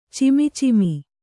♪ cimi cimi